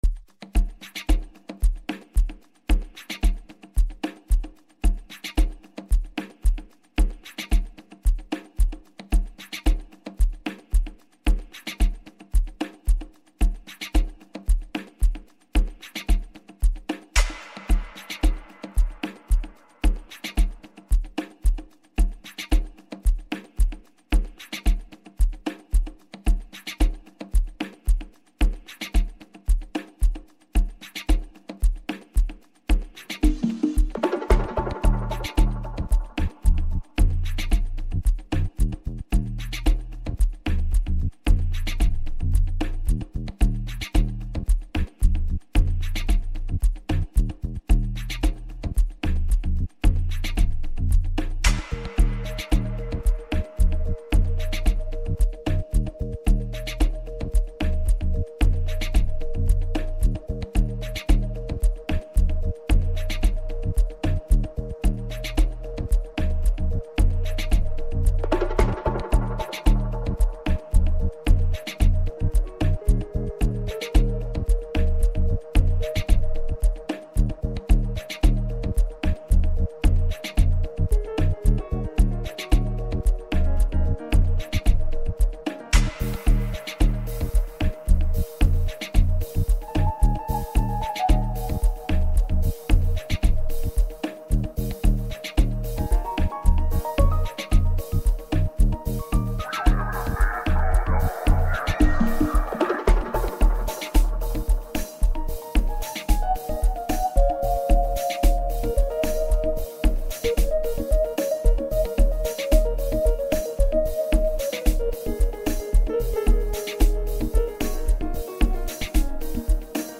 Amapiano, DJ Mix, Hip Hop